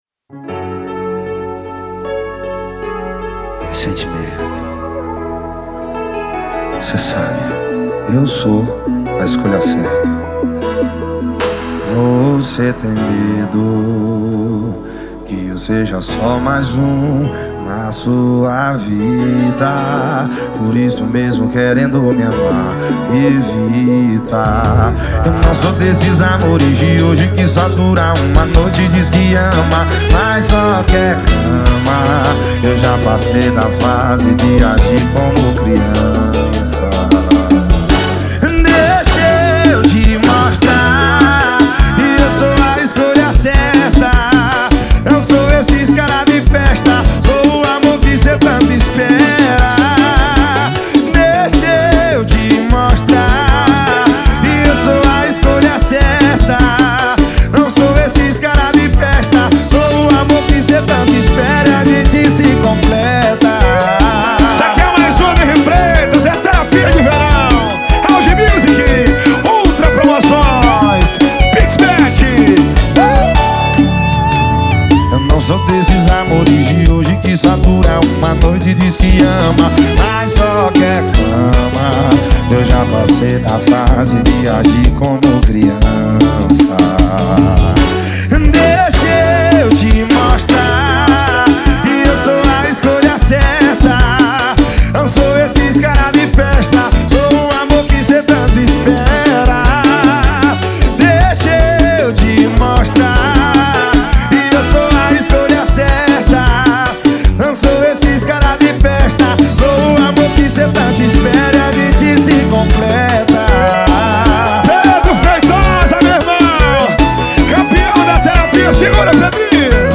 2024-02-14 18:28:48 Gênero: Forró Views